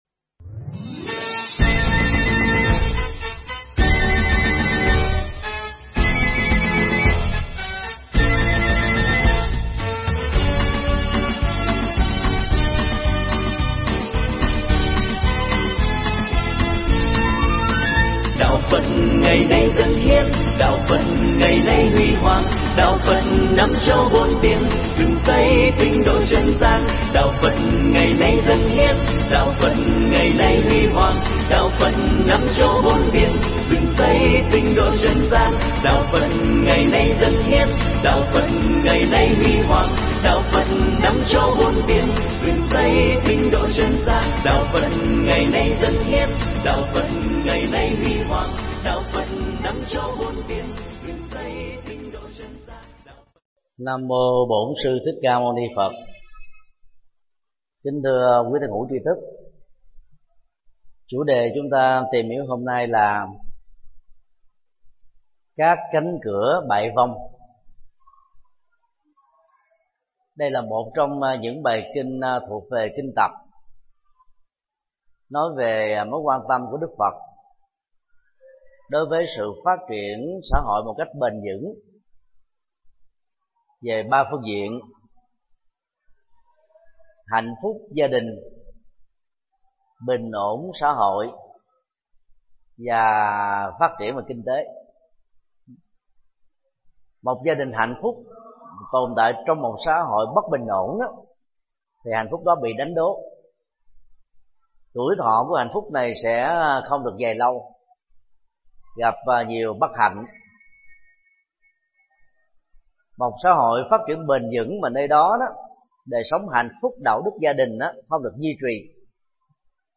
Cánh cửa bại vong 01 – Thầy Thích Nhật Từ Thuyết Pháp mp3
Tải mp3 Pháp Thoại Cánh cửa bại vong 01 – Do Thầy Thích Nhật Từ giảng tại chùa Xá Lợi, ngày 21 tháng 8 năm 2011